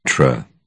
uk_phonetics_sound_tra_2023feb.mp3